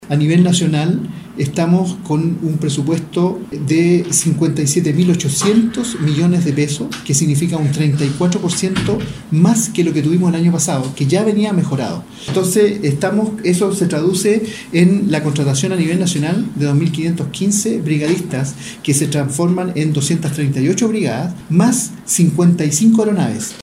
Por esta razón, se decidió realizar la inversión, la cual representa un aumento del 34% del presupuesto respecto a 2018, según aseguró el director ejecutivo de la entidad, José Manuel rebolledo.